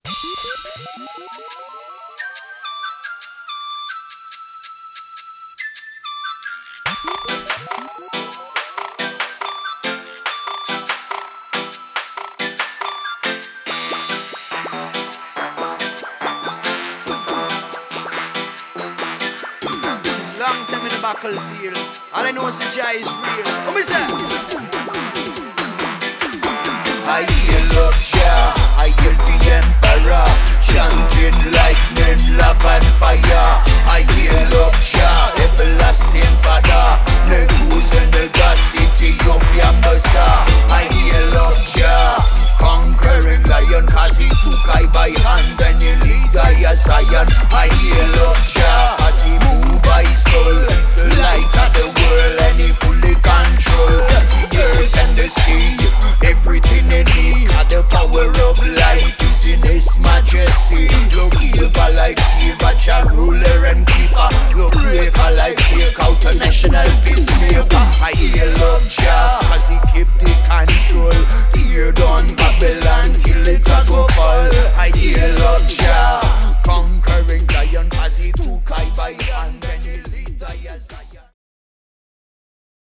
A tuff steppas tune